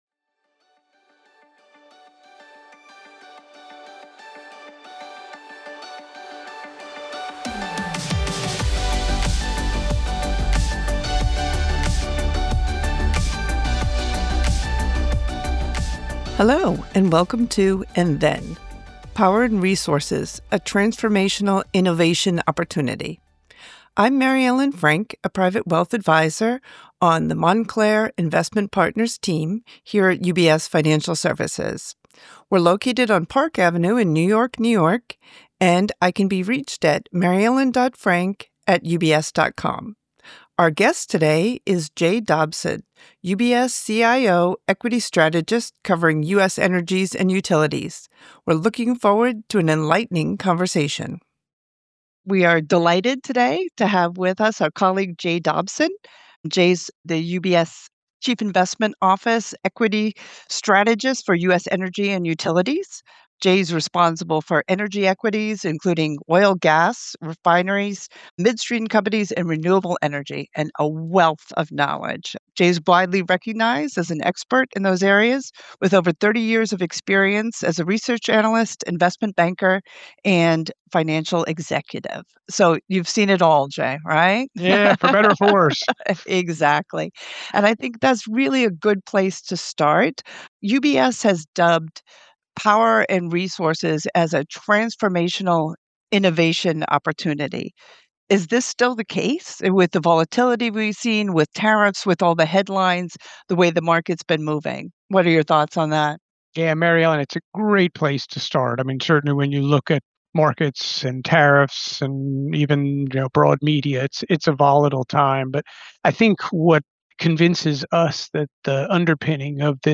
Can the US meet the ever rising demand for electricity? Join us for an enlightening conversation that explores Power and Resources. We will discuss how our supply is created and how AI's insatiable demand for energy has changed the playing field.